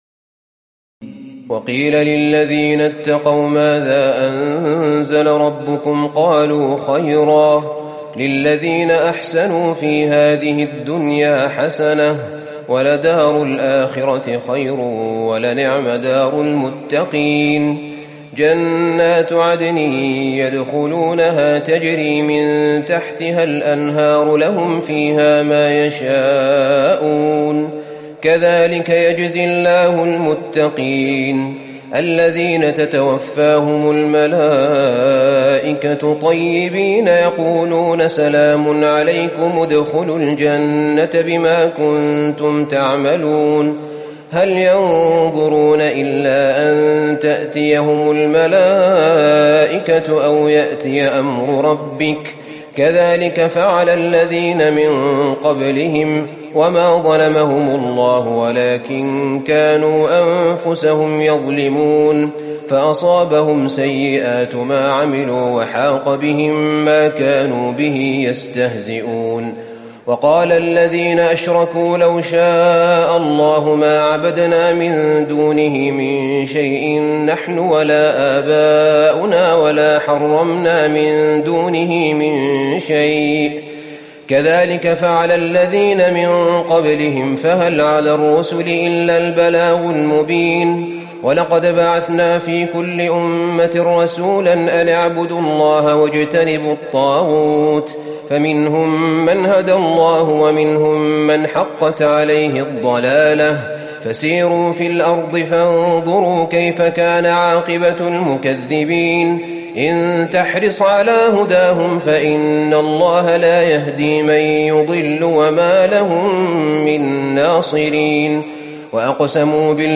تراويح الليلة الرابعة عشر رمضان 1438هـ من سورة النحل (30-128) Taraweeh 14 st night Ramadan 1438H from Surah An-Nahl > تراويح الحرم النبوي عام 1438 🕌 > التراويح - تلاوات الحرمين